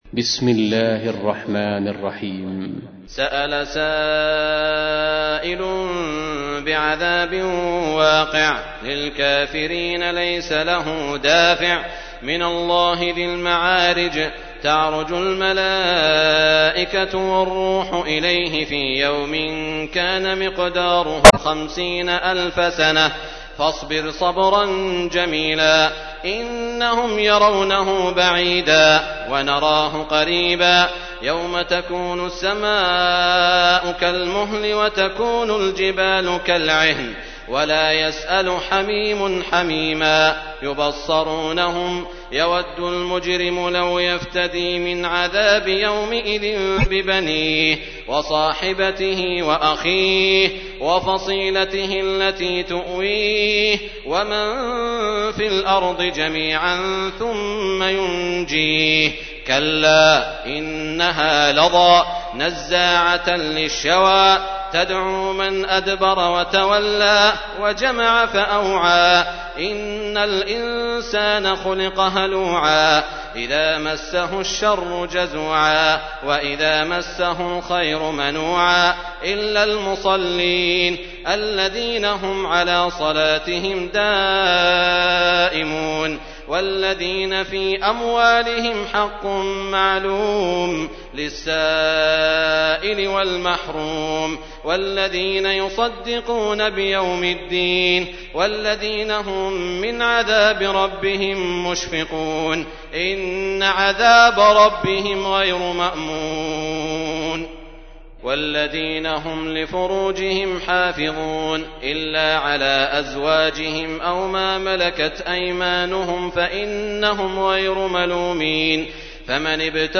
تحميل : 70. سورة المعارج / القارئ سعود الشريم / القرآن الكريم / موقع يا حسين